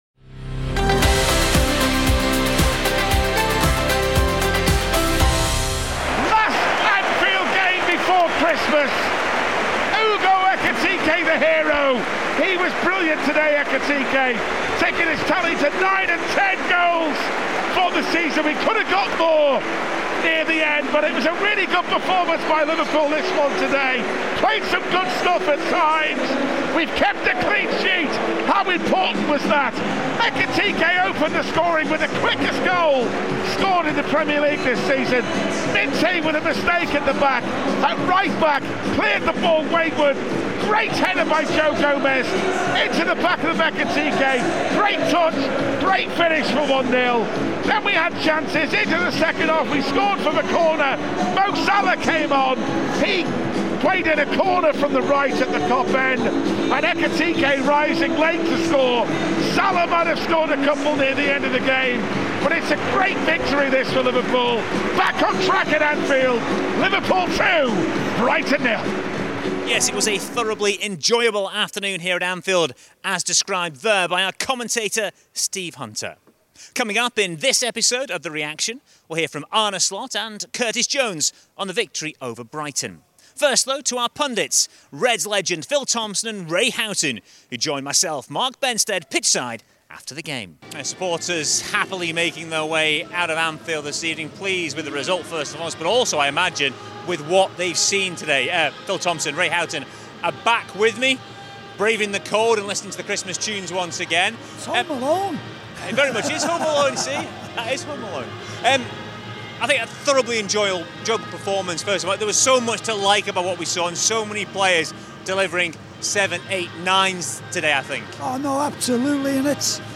Arne Slot and Curtis Jones give their thoughts on Liverpool’s 2-0 win over Brighton after Hugo Ekitike’s brace secured all three points in their 16th Premier League fixture of the season.
Reds legends Phil Thompson and Ray Houghton also provide pitchside analysis at Anfield.